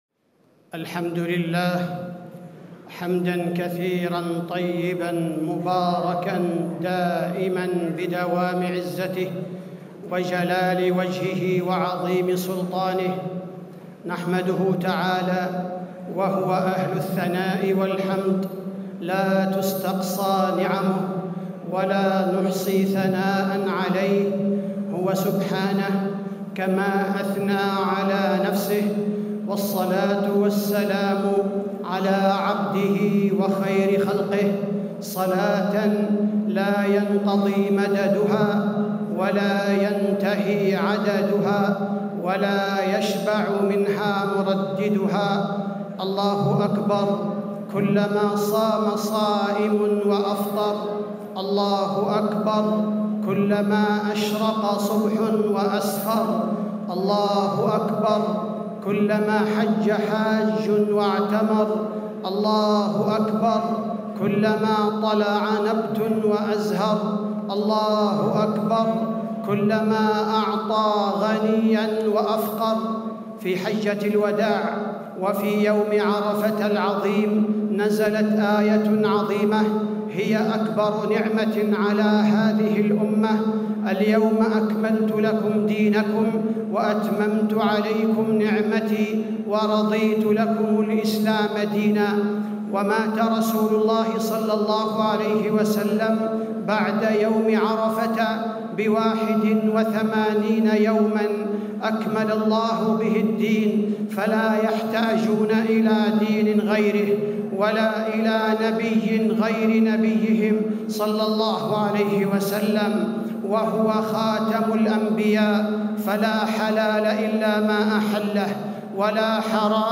خطبة عيد الأضحى - المدينة - الشيخ عبد الباري الثبيتي - الموقع الرسمي لرئاسة الشؤون الدينية بالمسجد النبوي والمسجد الحرام
تاريخ النشر ١٠ ذو الحجة ١٤٣٦ هـ المكان: المسجد النبوي الشيخ: فضيلة الشيخ عبدالباري الثبيتي فضيلة الشيخ عبدالباري الثبيتي خطبة عيد الأضحى - المدينة - الشيخ عبد الباري الثبيتي